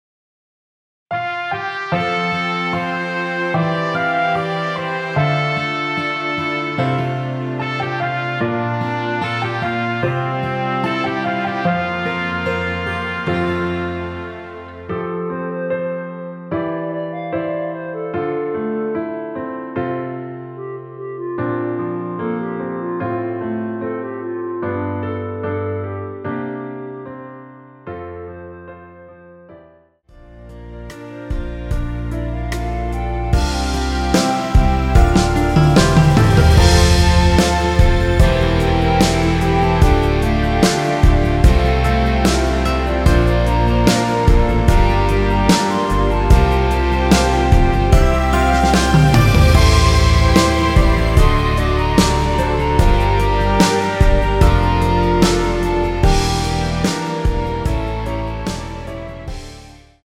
원키에서(+5)올린 멜로디 포함된 MR입니다.(미리듣기 확인)
앞부분30초, 뒷부분30초씩 편집해서 올려 드리고 있습니다.
중간에 음이 끈어지고 다시 나오는 이유는